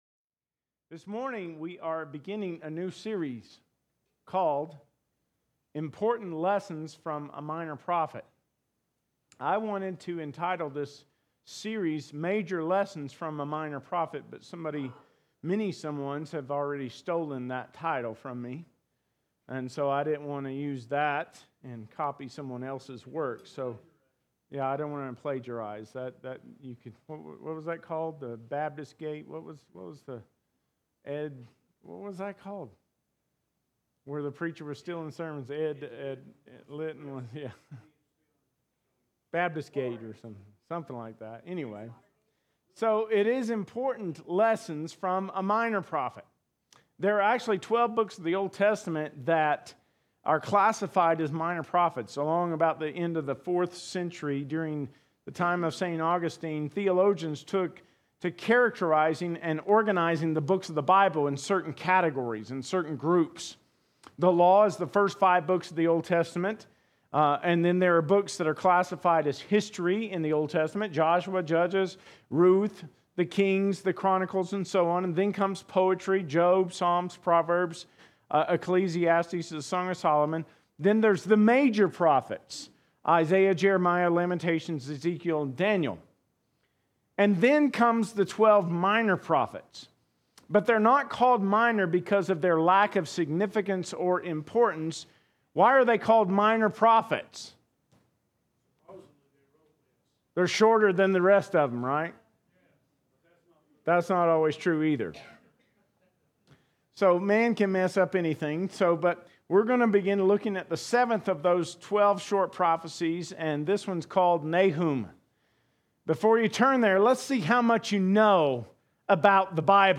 2024 Sermons - Smith Valley Baptist Church